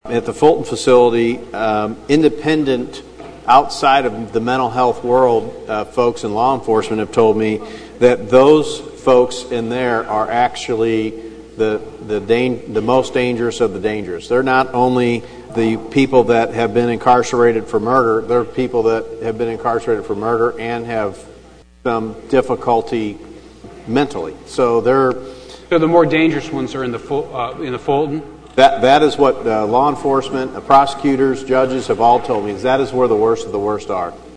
The legislature has limited workers at those two facilities to no more than 12 hours in each 24-hour period.  Senate sponsor Mike Kehoe of Jefferson City has heard reports of people working double shifts, and more, at times.
He was speaking to St. Louis Senator Jim Lembke.